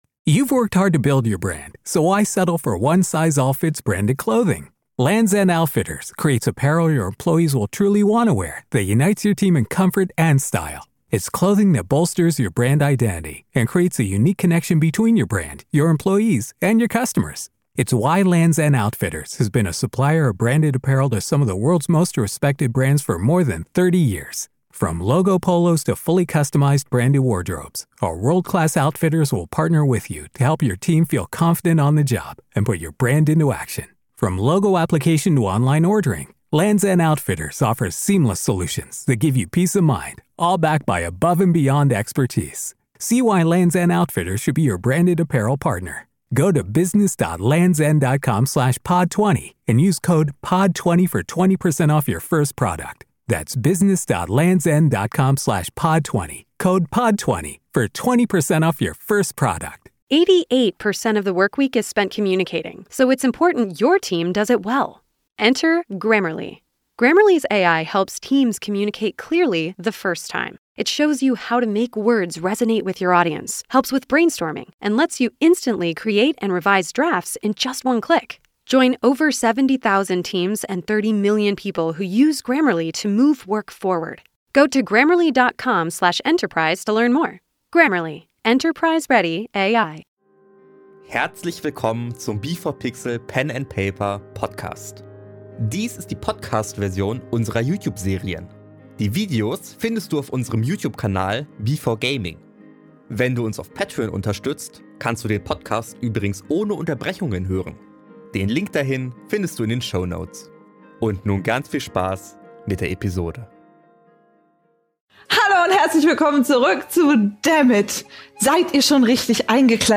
Wir spielen auf dem YouTube B4Gaming regelmäßig Pen and Paper und veröffentlichen dort unsere Serien. Dies hier ist die Podcast-Version mit Unterbrechungen.